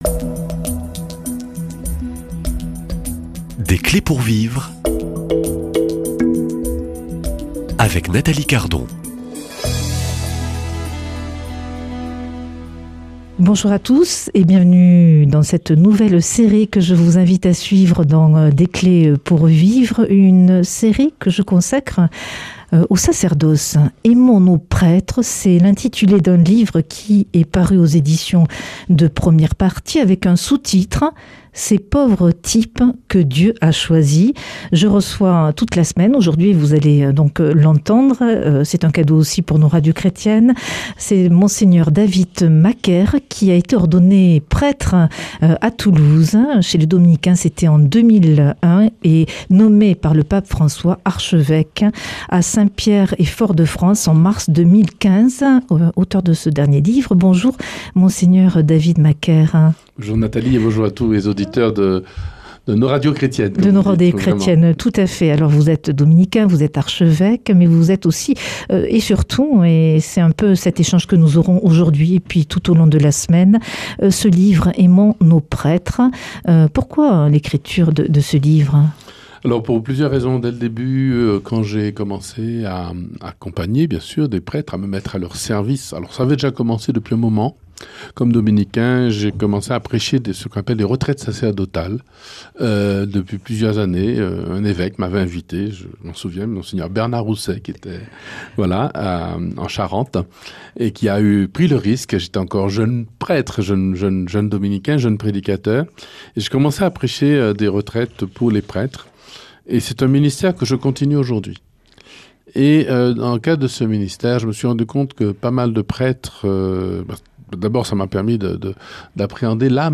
Invité : Mgr David Macaire, ordonné prêtre chez les Dominicains à Toulouse en 2001.